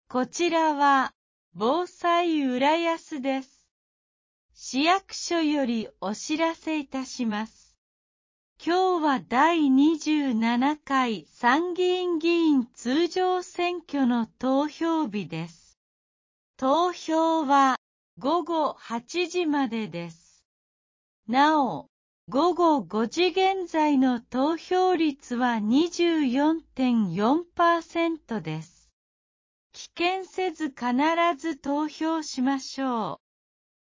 第27回参議院議員通常選挙 | 浦安市防災行政無線 放送内容掲載ホームページ